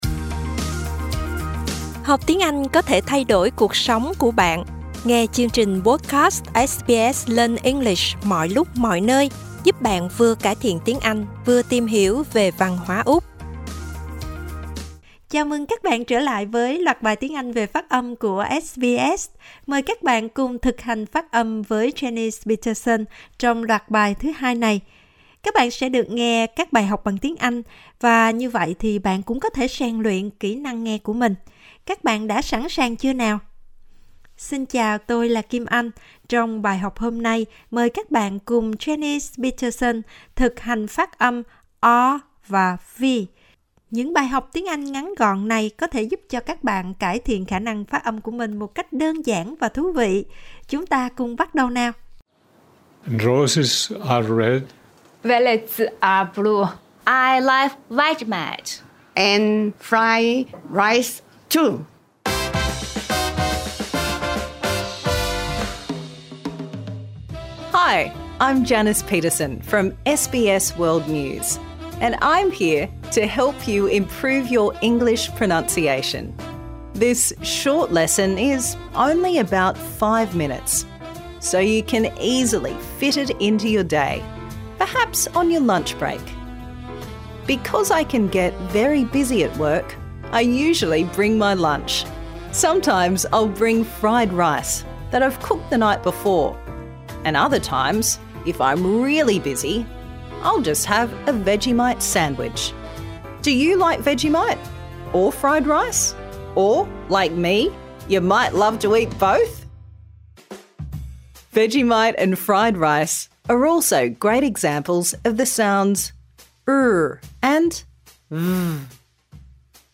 Improve your pronunciation | Season 2
Key Points Learning objectives: Can pronounce /r/ and /v/.